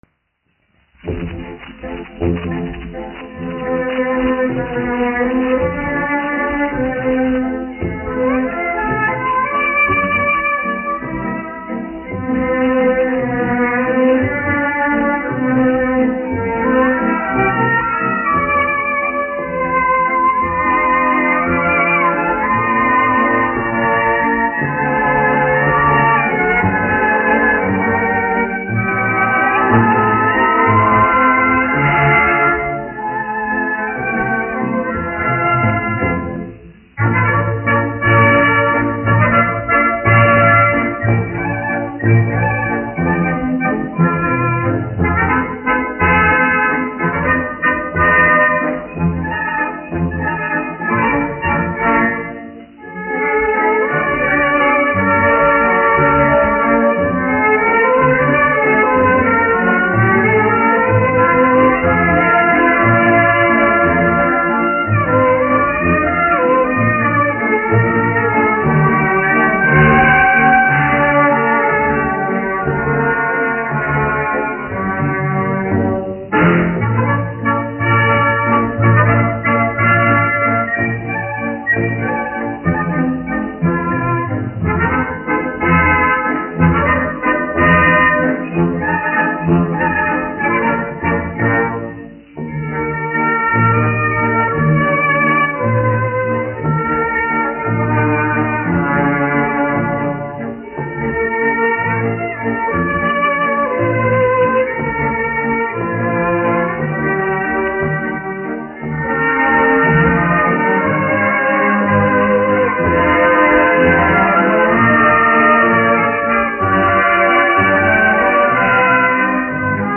1 skpl. : analogs, 78 apgr/min, mono ; 25 cm
Valši
Orķestra mūzika
Latvijas vēsturiskie šellaka skaņuplašu ieraksti (Kolekcija)